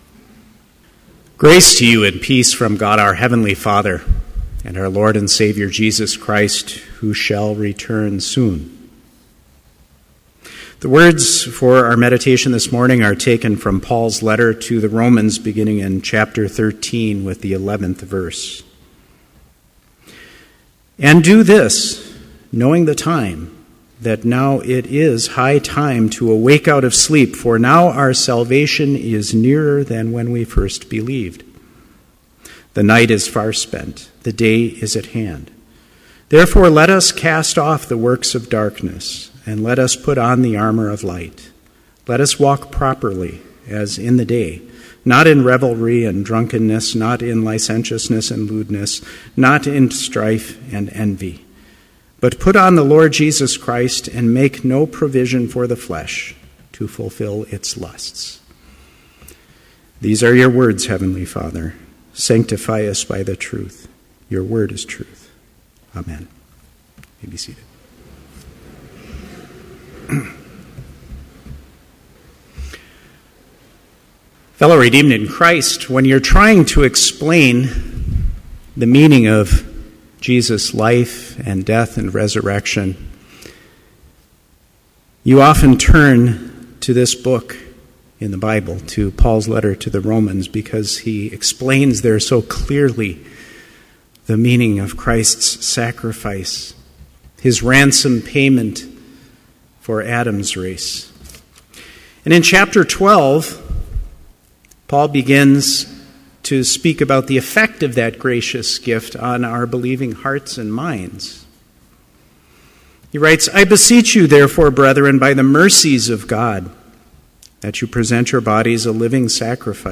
Complete Service
• Prelude
• Hymn 94, vv. 1, 5 & 7, O How Shall I Receive Thee
• Homily
This Chapel Service was held in Trinity Chapel at Bethany Lutheran College on Monday, December 2, 2013, at 10 a.m. Page and hymn numbers are from the Evangelical Lutheran Hymnary.